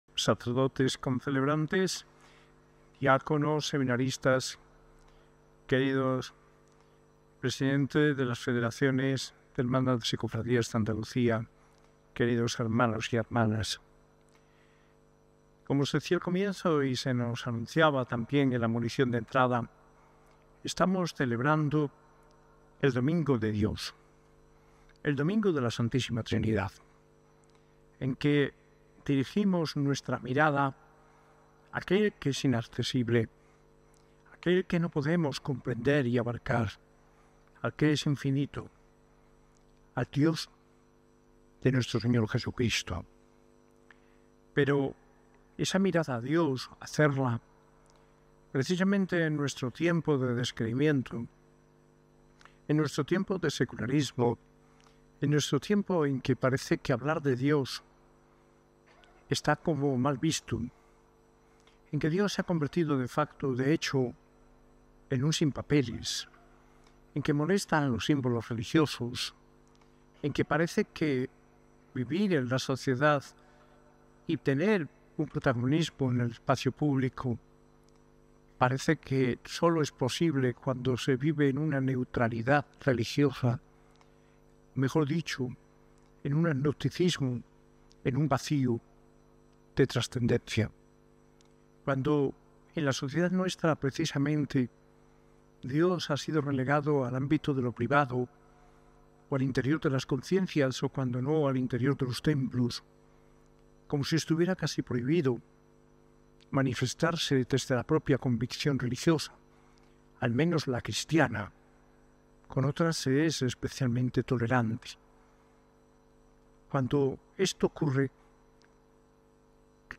Homilía en el día de la Santa Trinidad, de manos del arzobispo Mons. José María Gil Tamayo, celebrada en la S.A.I Catedral el 15 de junio de 2025.